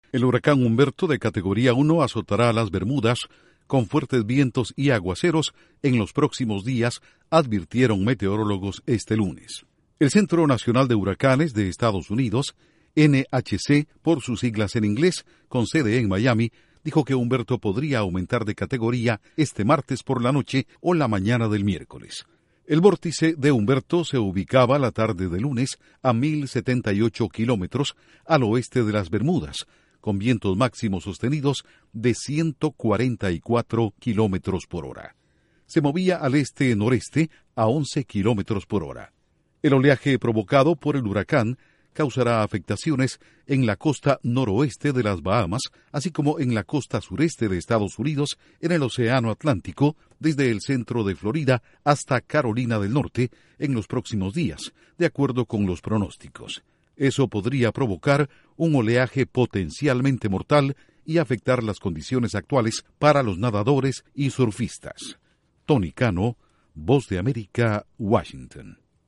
Advierten que el huracán Humberto azotará las Bermudas y afectará desde Florida hasta Carolina del Norte. Informa desde la Voz de América en Washington